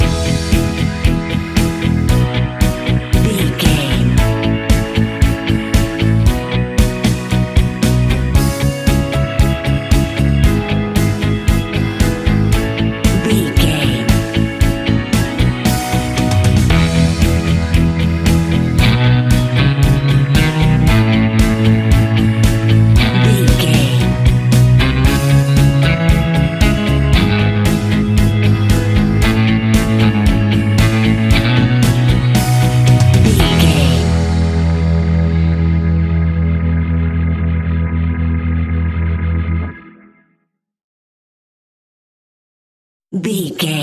Epic / Action
Fast paced
Ionian/Major
pop rock
indie pop
fun
energetic
uplifting
motivational
cheesy
acoustic guitars
drums
bass guitar
electric guitar
piano
electric piano
organ